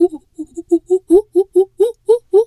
pgs/Assets/Audio/Animal_Impersonations/monkey_2_chatter_02.wav at master
monkey_2_chatter_02.wav